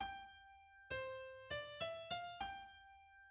The movement opens with a long introduction, beginning with the "cry of despair" that was the climax of the third movement, followed by the quiet presentation of a theme (another quotation from Hans Rott's Symphony No.1) which reappears as structural music in the choral section.